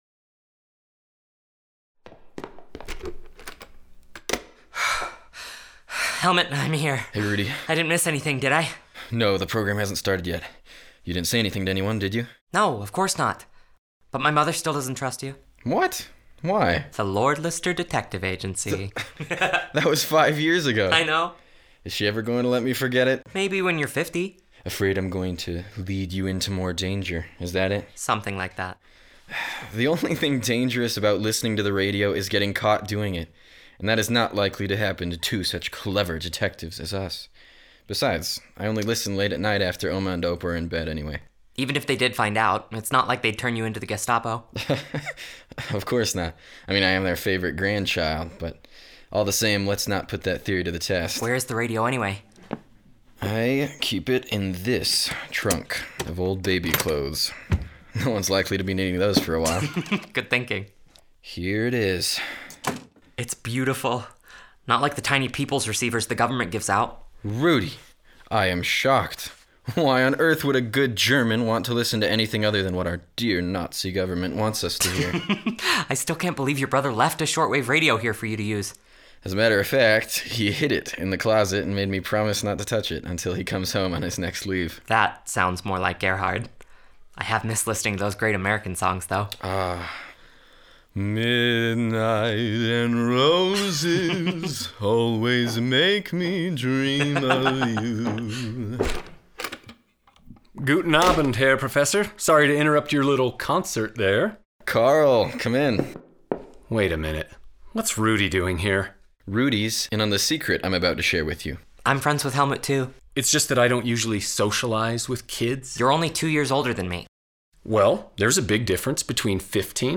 Resistance Movement tells the inspiring true story of the Hübener Group: Three teenagers in Nazi Germany, who risked everything to defy Hitler. **Performed as a Full-cast Radio Play with Live Sound Effects.
CD & Digital Download of the Full-Cast recording, performed with Live Sound Effects.
Recorded February 9, 2024 at Counterpoint Studios, Salt Lake City, UT